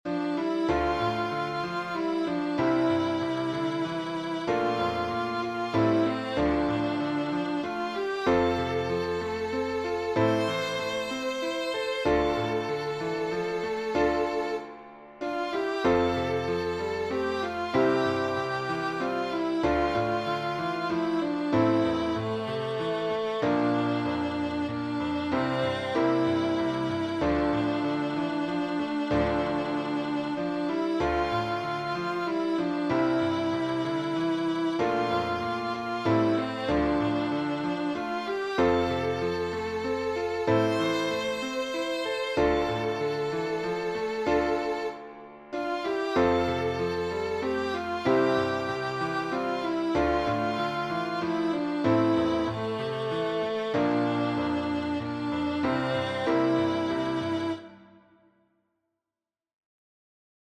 Hymn Composer